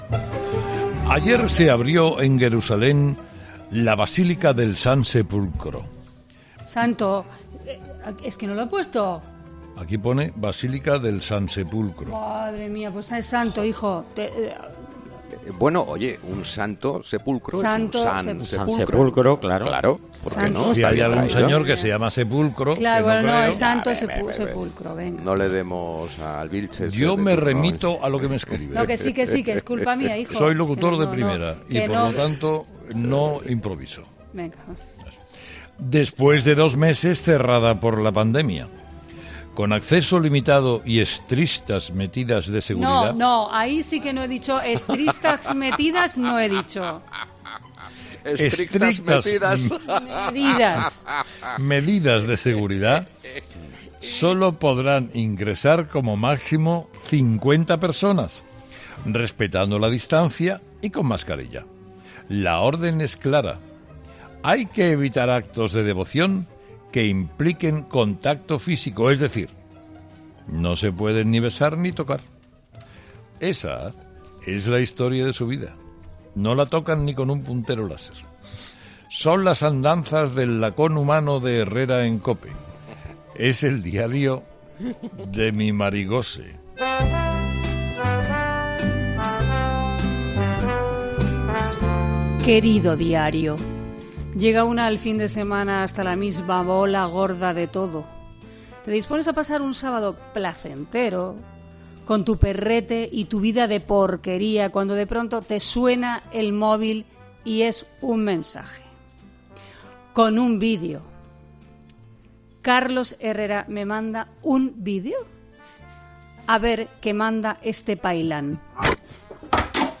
Presentado por Carlos Herrera, el comunicador más escuchado de la radio española, es un programa matinal que se emite en COPE, de lunes a viernes, de 6 a 13 horas, y que siguen cada día más de dos millones y medio de oyentes, según el EGM.